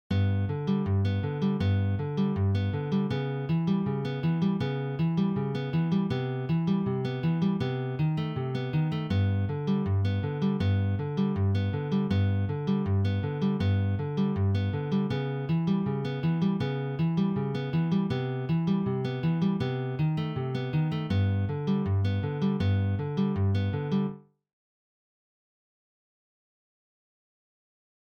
Pop/Rock/Elektronik
Gitarre (1)